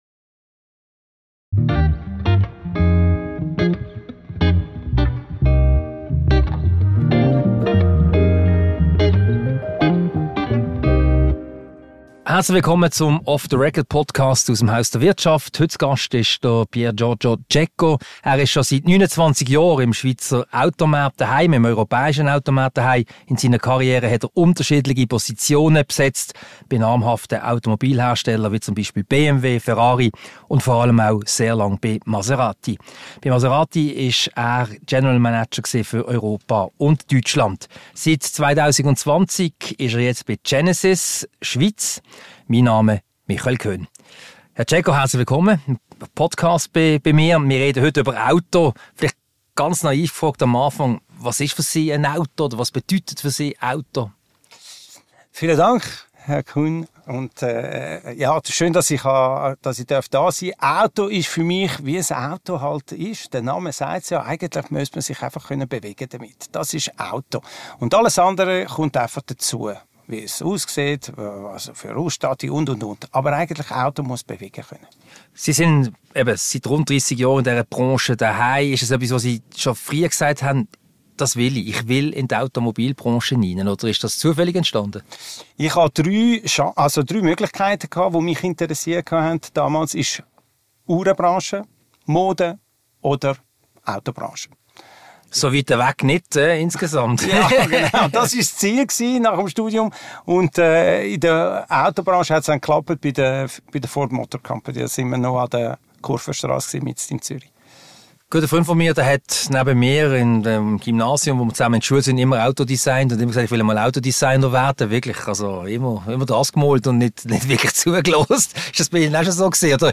Ein Gespräch über Luxusautos und Service im allgemeinen, Genesis im speziellen und den Automarkt.